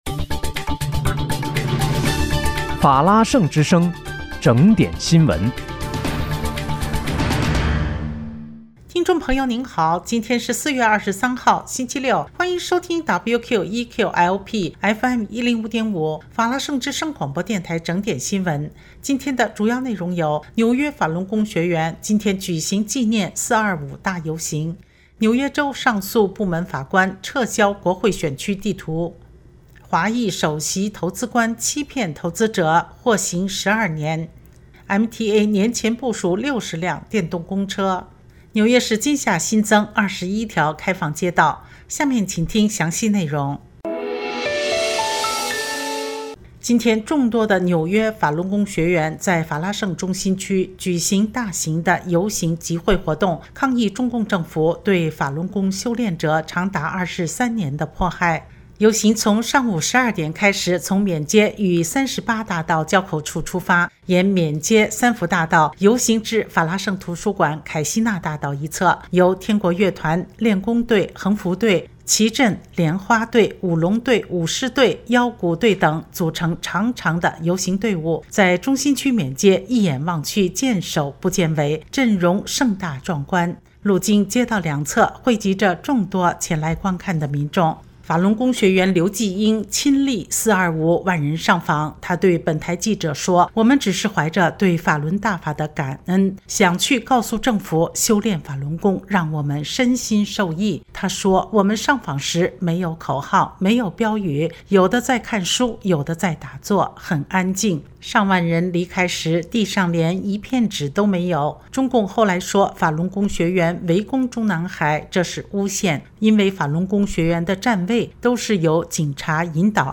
4月23日（星期六）纽约整点新闻